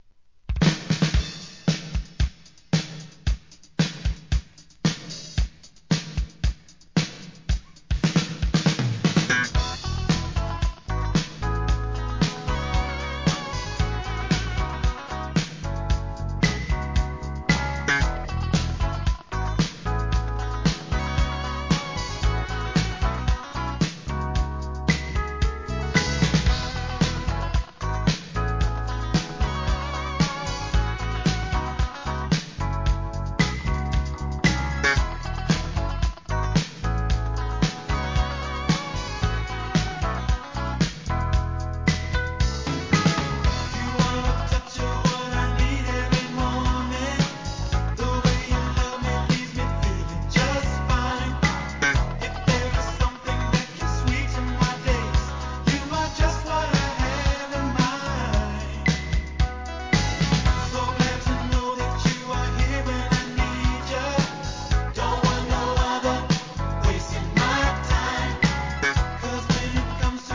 SOUL/FUNK/etc...
ダンスナンバー、アーバンメロウとバランス良い内容です!